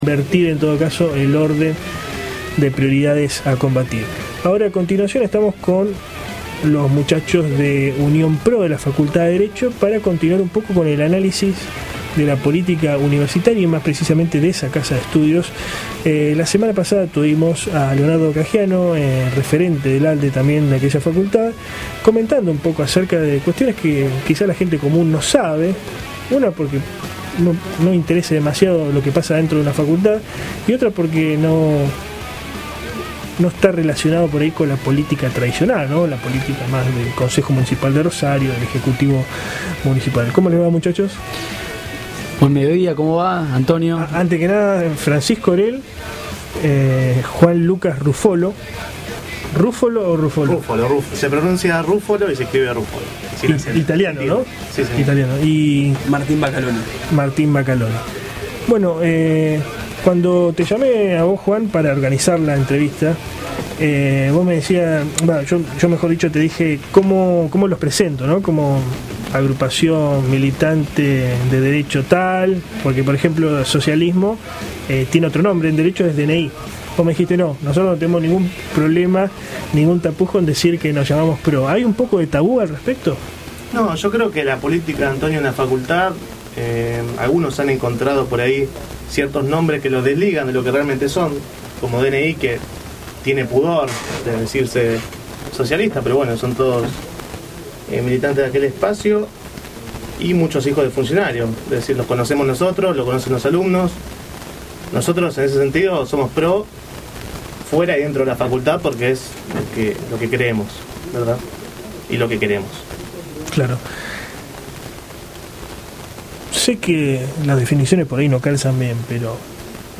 PRO FACULTAD DE DERECHO AUDIO ENTREVISTA